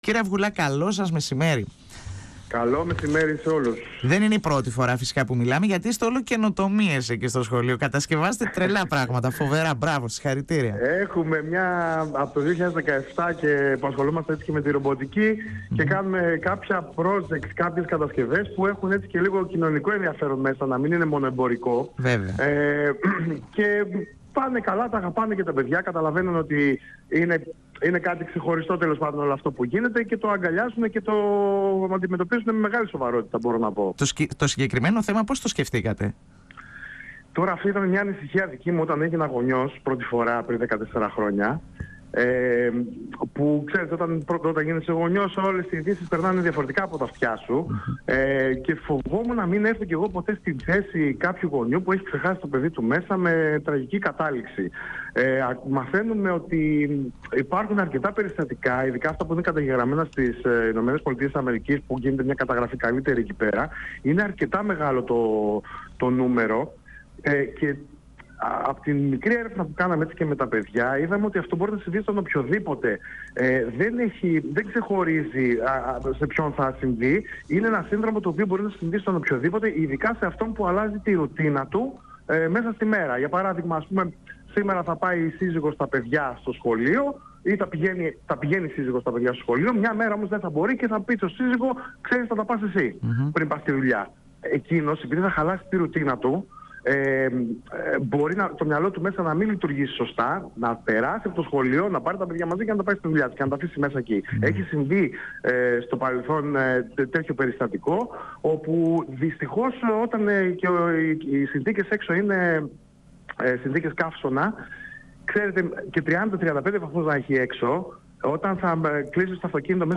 μιλώντας στην εκπομπή «Εδώ και Τώρα» του 102fm της ΕΡΤ3